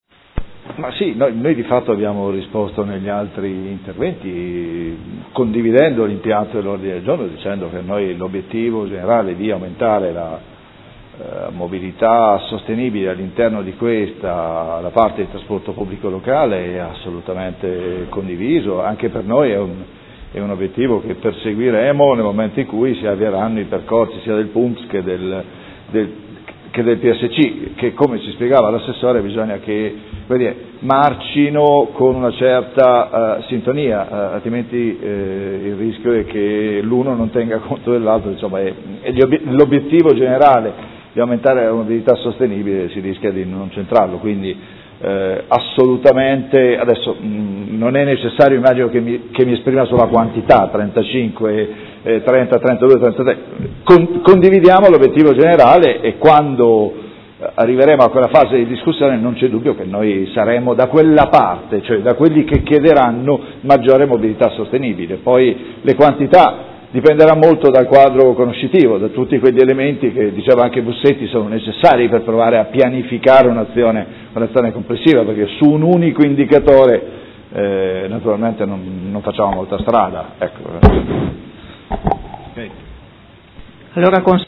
Seduta del 14/04/2016 Dibattito. Ordine del Giorno presentato dal Consigliere Montanini del Gruppo Consiliare CambiAMOdena avente per oggetto: Criteri di pianificazione urbanistica